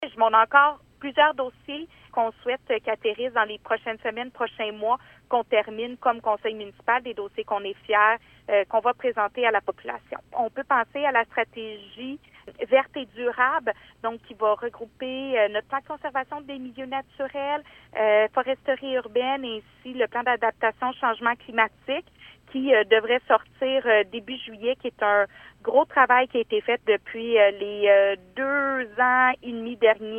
Mme Bourdon parle d’un important projet qui verra le jour le mois prochain : Granby, Bourdon sollicite 2e mandat, 02.06.25_Bourdon, clip Julie Bourdon souligne qu’elle présentera ses priorités cet automne, dans le respect du calendrier électoral.